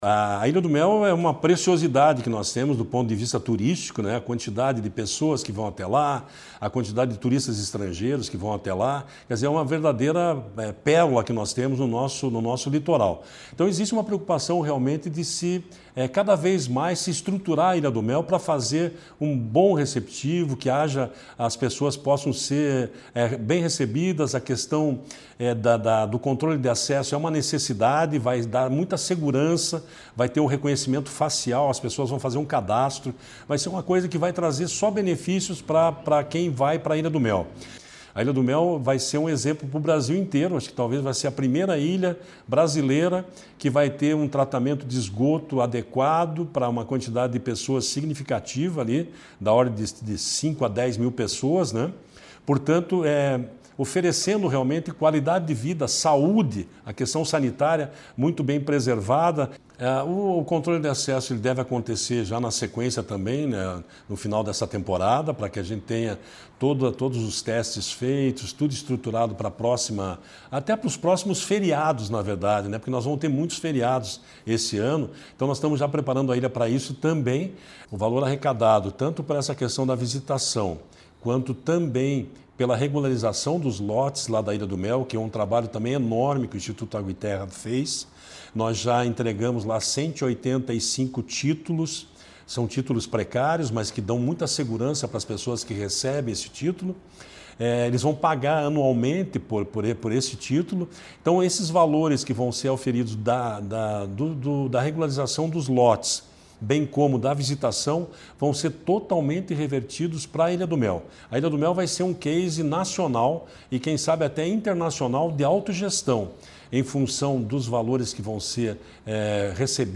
Sonora do diretor-presidente do IAT, Everton Souza, sobre as ações na Ilha do Mel